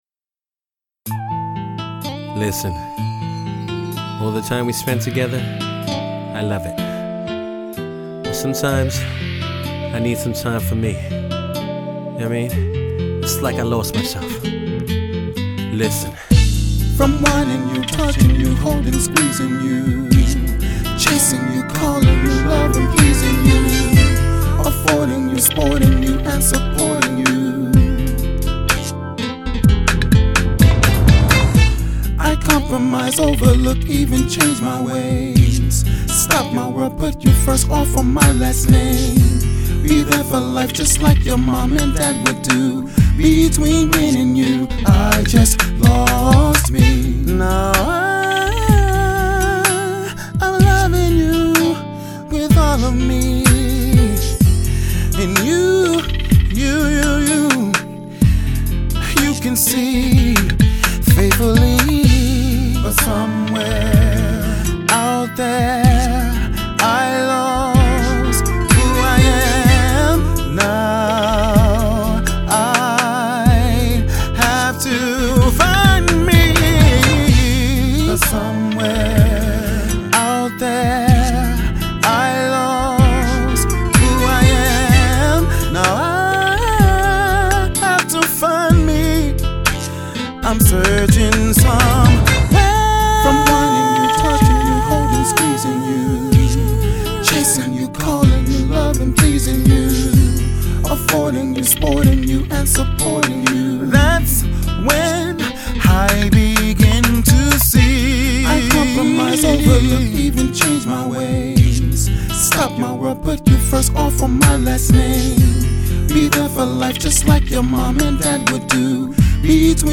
R&B